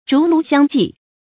舳舻相继 zhú lú xiāng jì 成语解释 舳：船尾。